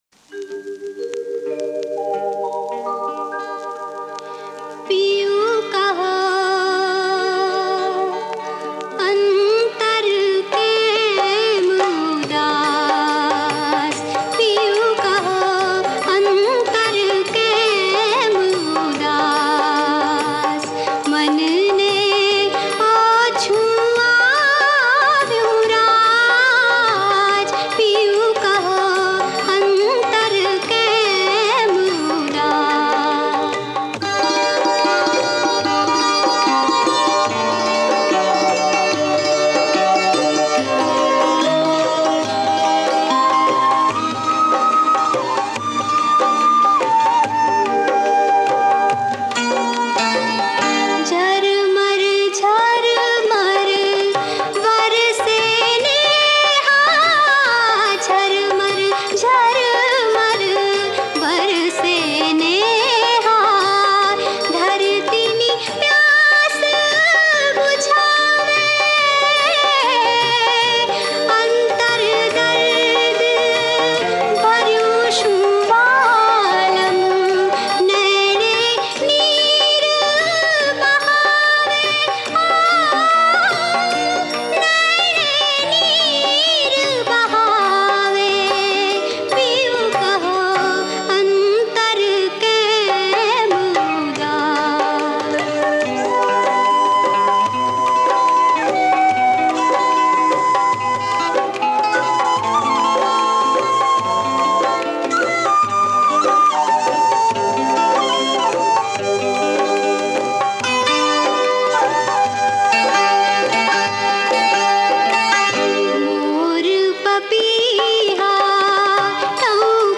ગ્રામોફોન રેકોર્ડ